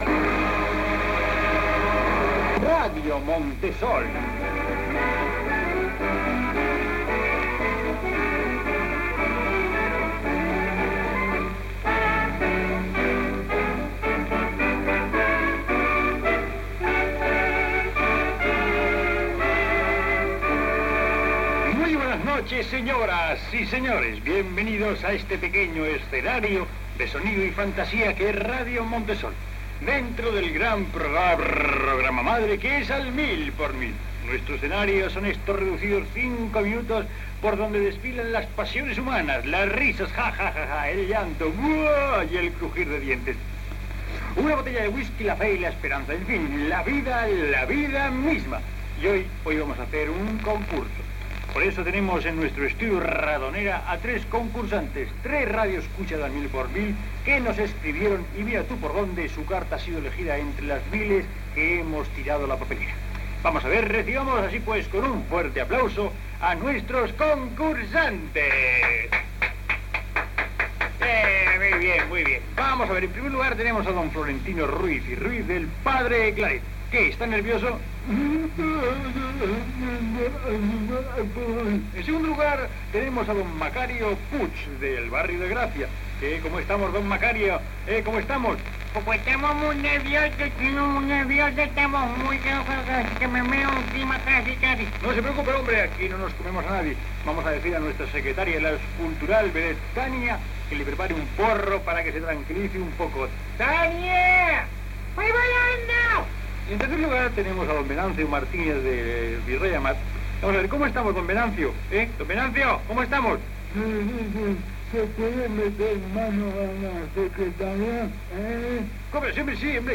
"Radio Montesol", presentació i concurs entre oïdors d'"Al mil por mil".
Entreteniment